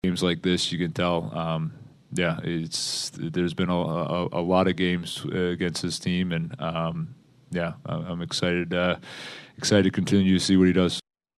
Coach Dan Muse says Sidney Crosby, with his 60th career goal against the Flyers, exemplifies one of the biggest rivalries in the NHL.